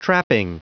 Prononciation du mot trapping en anglais (fichier audio)
Prononciation du mot : trapping
trapping.wav